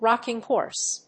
アクセントrócking hòrse